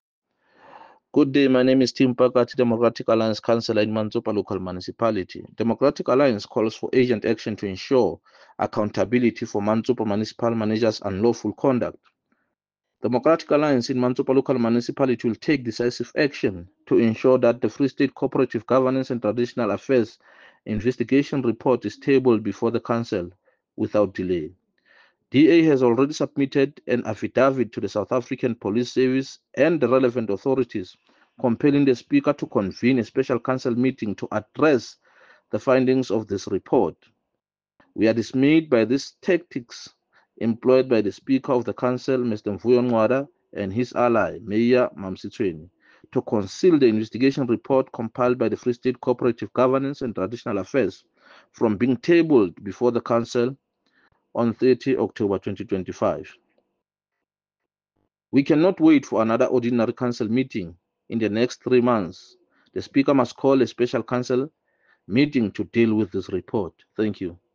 Sesotho soundbites by Cllr Tim Mpakathe.